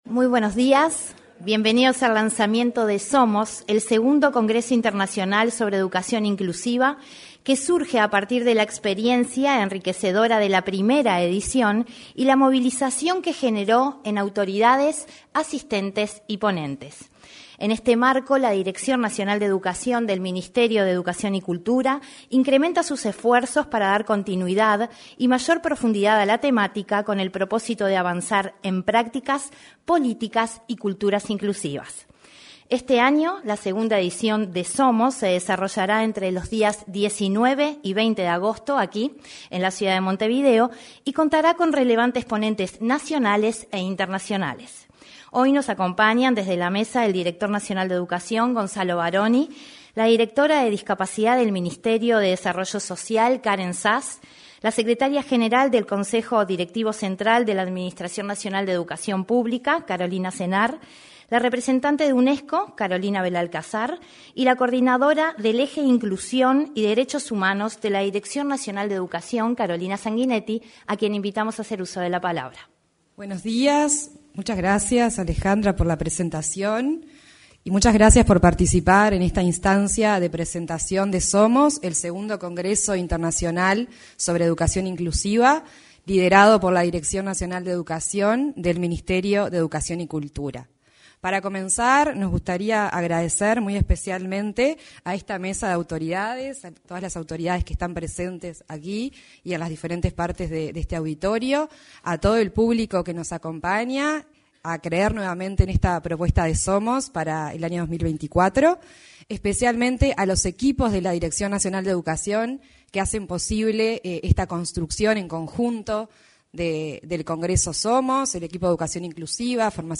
Lanzamiento de Somos II: Congreso Internacional sobre Educación Inclusiva 06/06/2024 Compartir Facebook X Copiar enlace WhatsApp LinkedIn Este jueves 6, se realizó la presentación del Congreso Internacional sobre Educación Inclusiva.